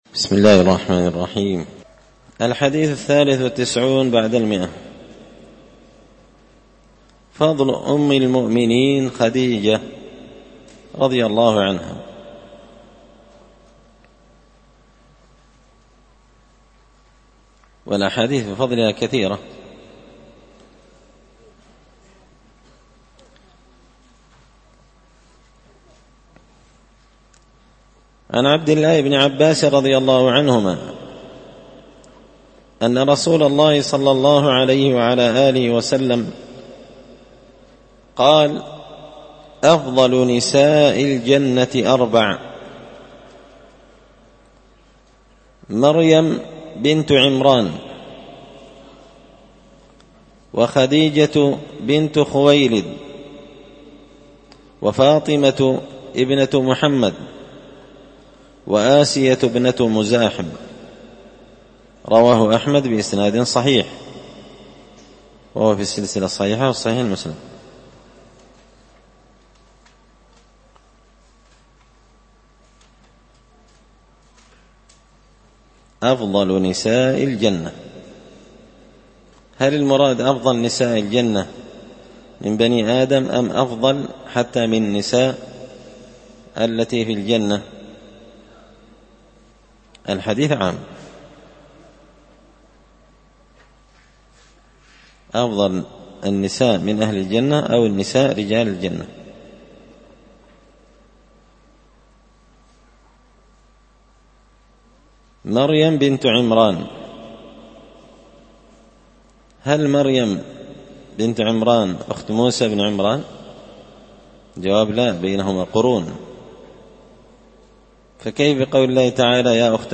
كتاب العشرينيات في عقيدة أهل السنة والأثر- الدرس 207